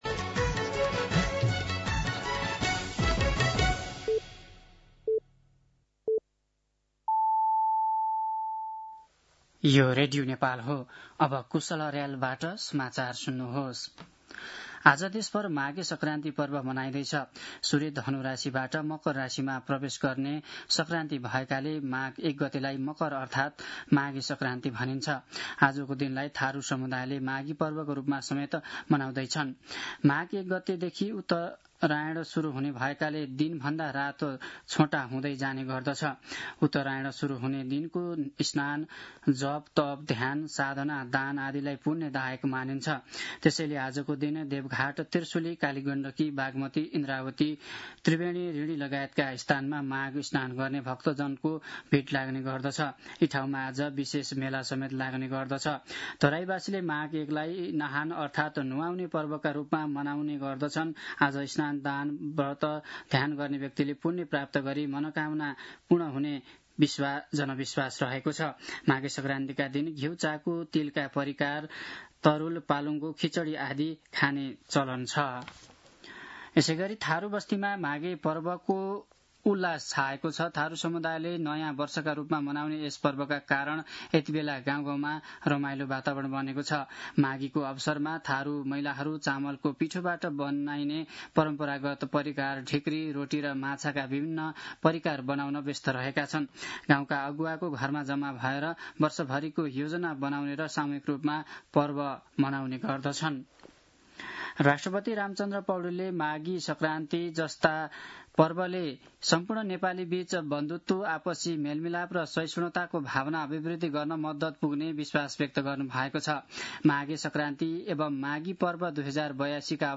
दिउँसो १ बजेको नेपाली समाचार : १ माघ , २०८२
1-pm-Nepali-News-6.mp3